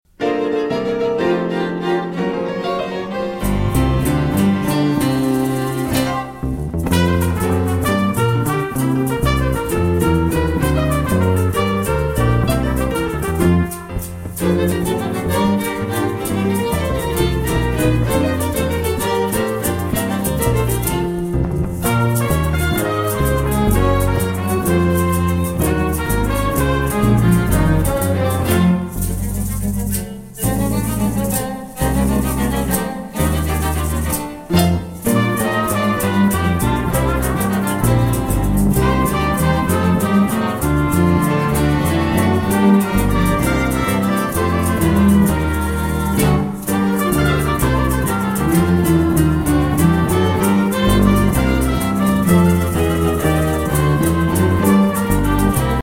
Eine gelungene Mischung aus Klassik und Jazz -
(Aufnahme: Live beim Jazz-Fest Franken 2006)